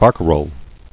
[bar·ca·role]